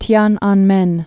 (tyänänmĕn)